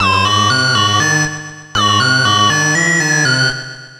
Funny Boned C# 120.wav